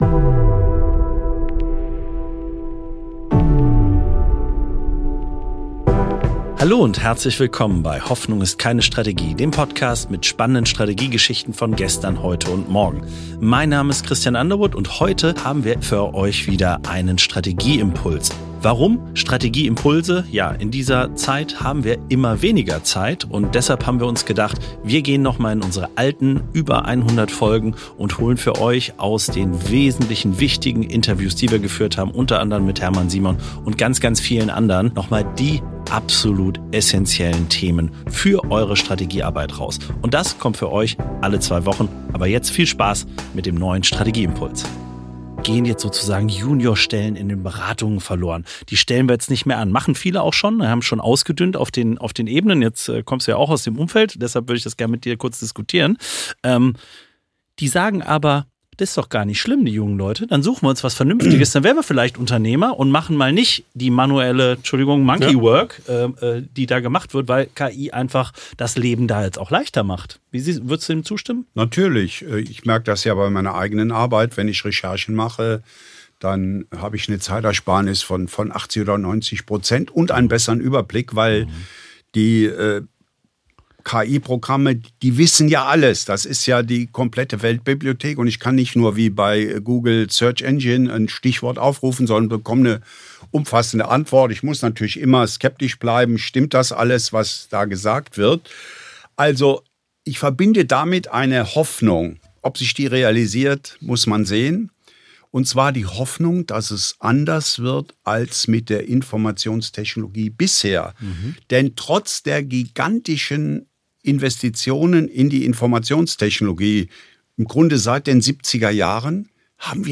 zeigt im Gespräch, warum moderne Führung immer zwei Seiten braucht: